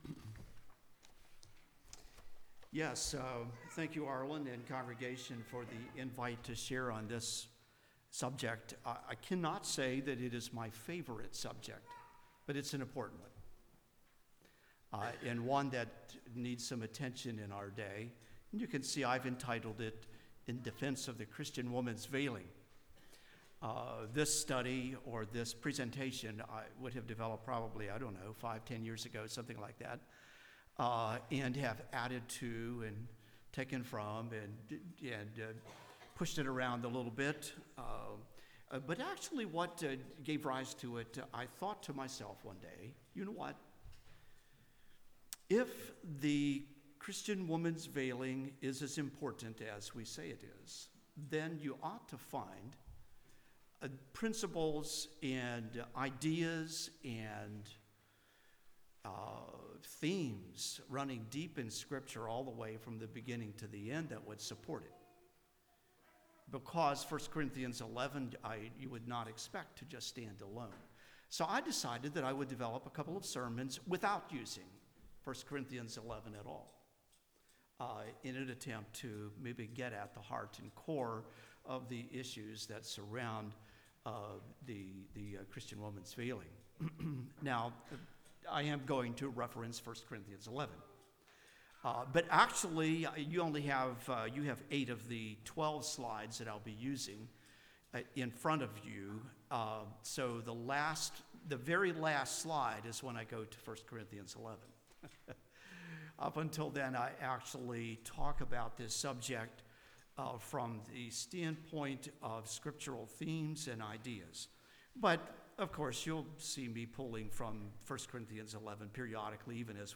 Service Type: Message